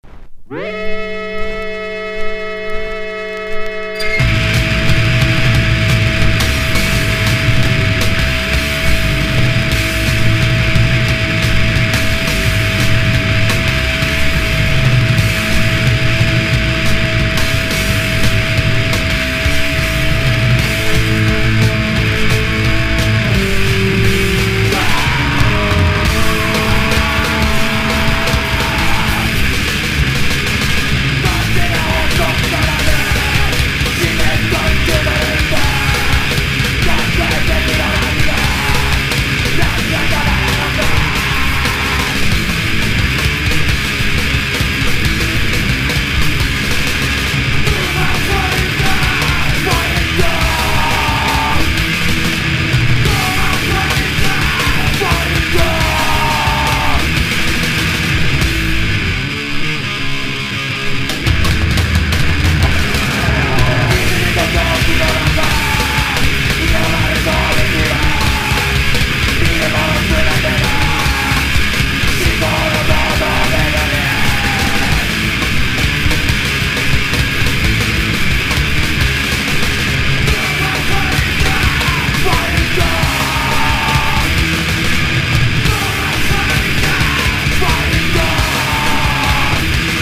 PUNK / HARDCORE